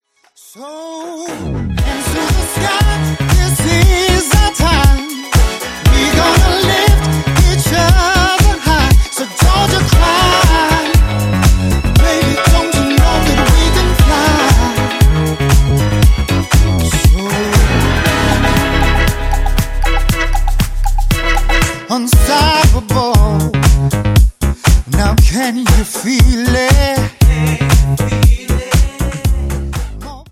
ジャンル(スタイル) NU DISCO / HOUSE